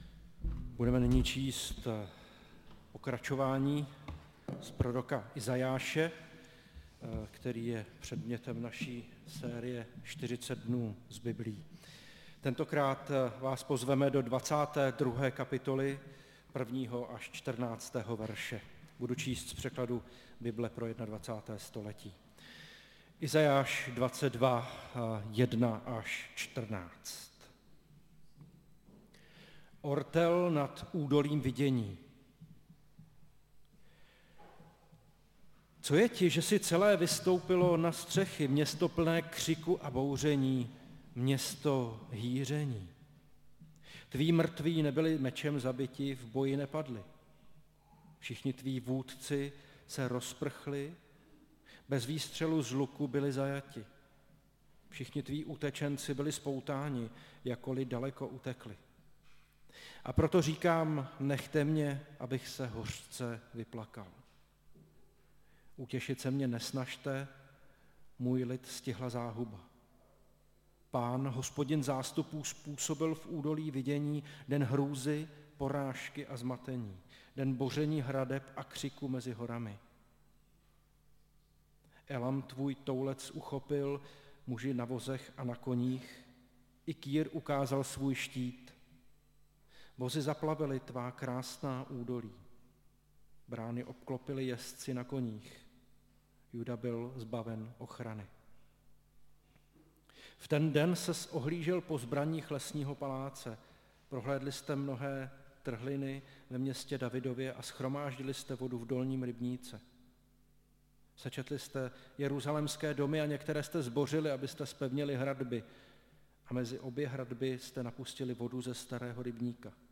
Nedělní kázání – 12.3.2023 Tváří v tvář Božímu soudu